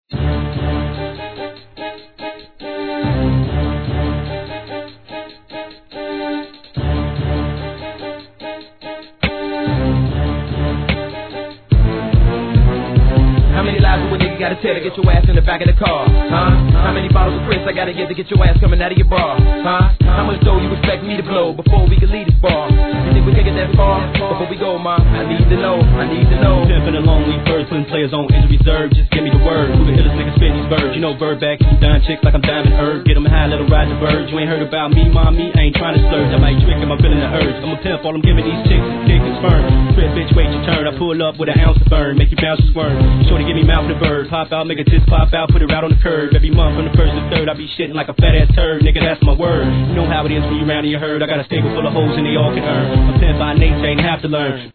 G-RAP/WEST COAST/SOUTH
OH州シンシナティ産スムースG！ ドリーミーな上音が印象的なA面に